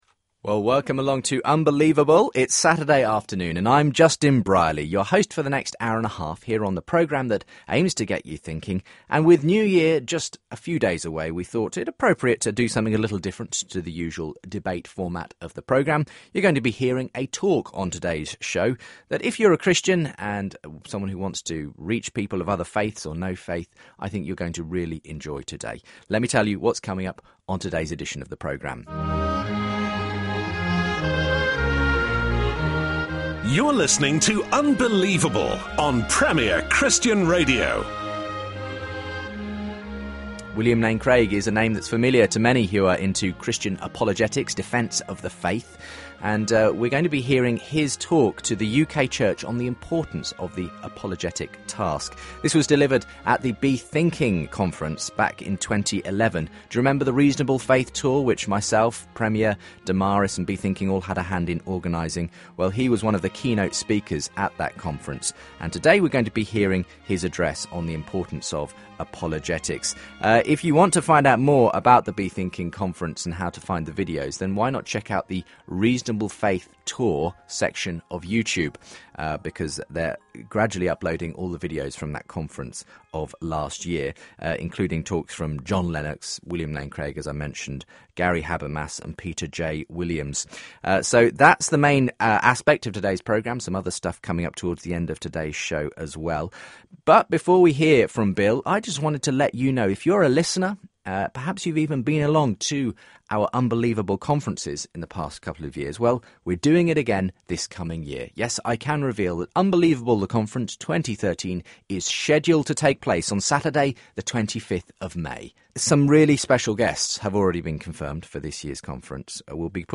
[This contains Dr. Craig’s lecture and the Q&A that followed which is found starting at the 3:24 mark, ending at the 1:05:25 mark of the audio with small breaks in between.]